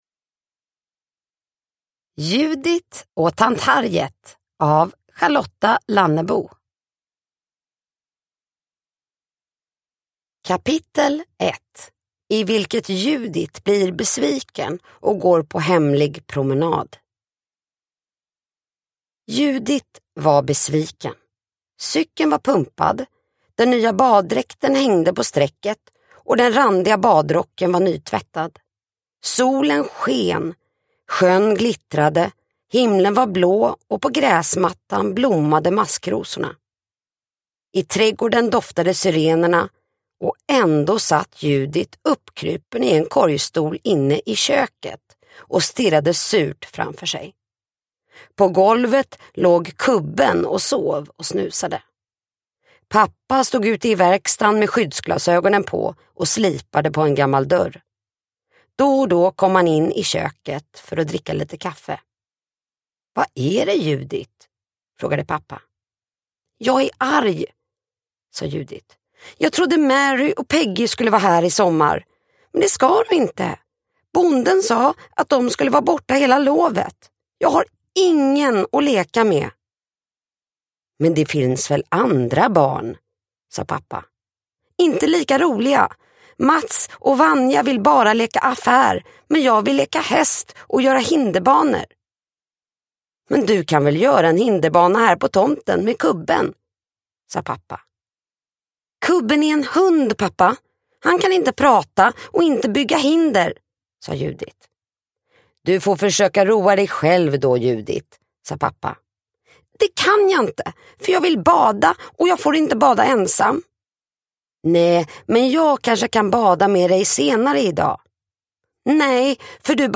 Judit och tant Harriet – Ljudbok – Laddas ner